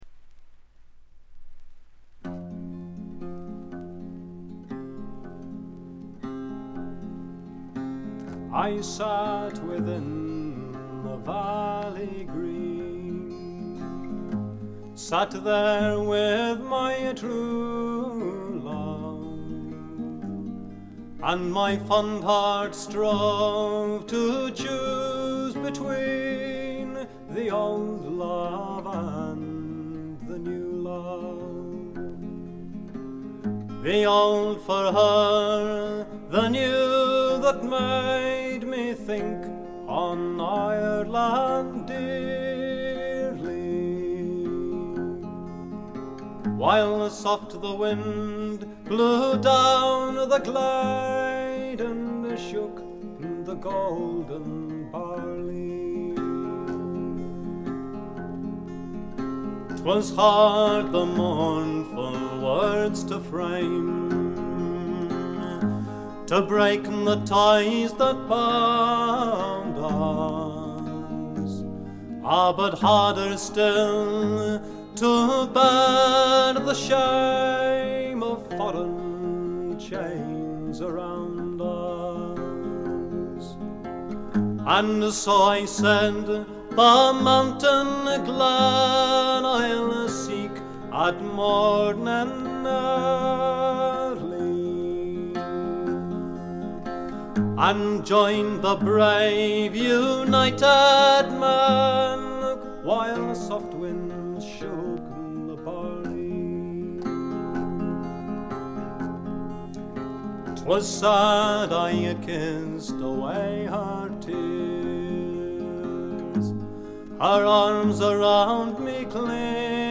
ところどころで軽微なチリプチ。目立つノイズはありません。
英国フォーク必聴盤。
Stereo盤。
試聴曲は現品からの取り込み音源です。